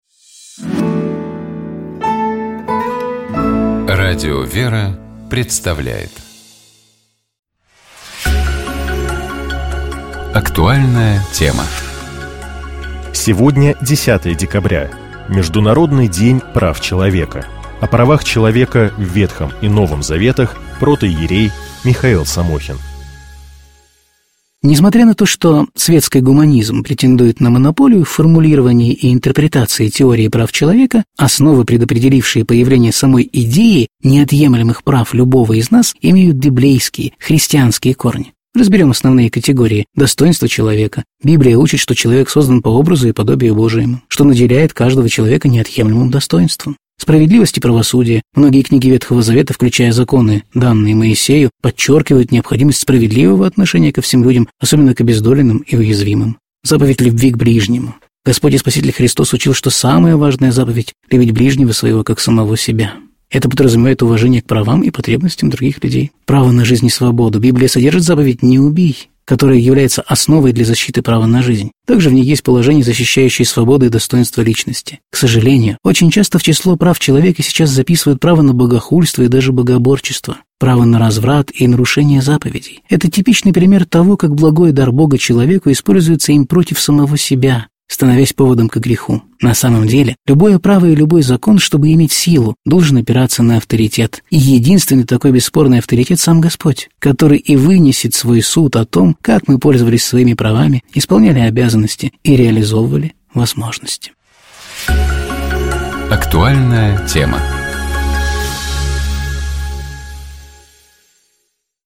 У нас в гостях был митрополит Ахалкалакский, Кумурдойский и Карсский Николай (Пачуашвили), заместитель председателя отдела миссии и евангелизации Грузинской Православной Церкви.